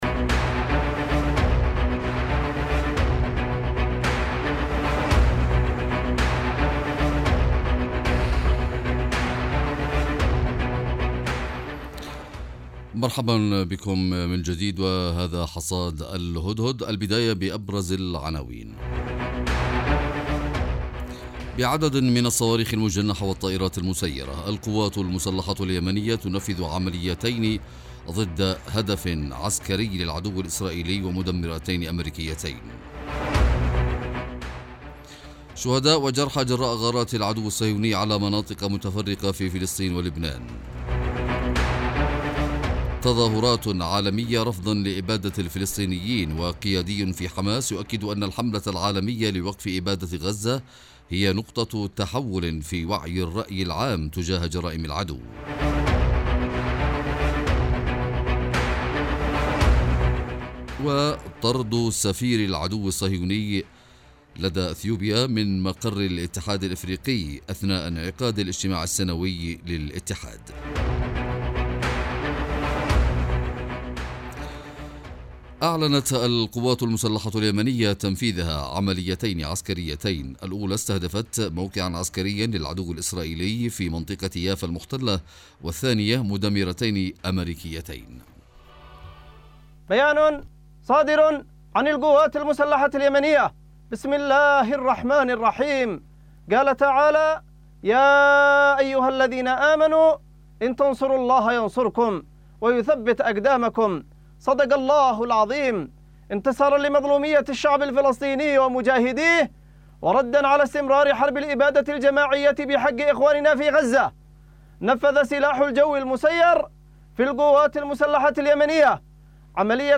موسيقى